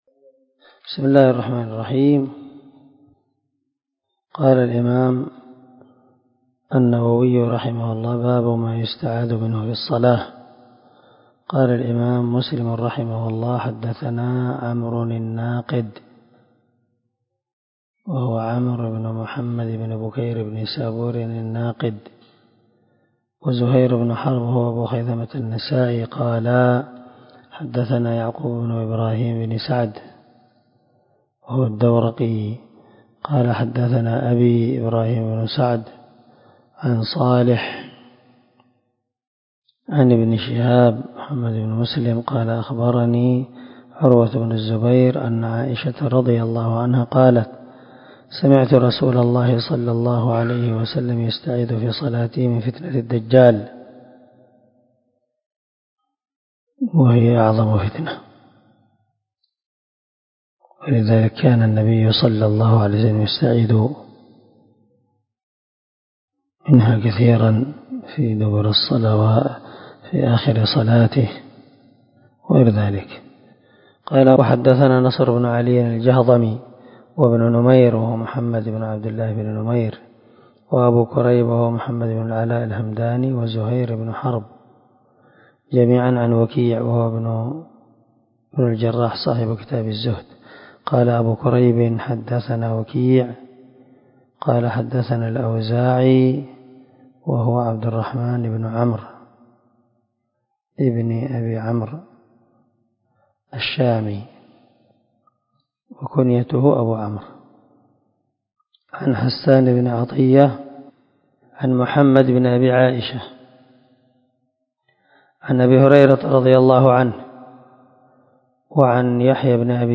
372الدرس 44 من شرح كتاب المساجد ومواضع الصلاة حديث رقم ( 587 - 588 ) من صحيح مسلم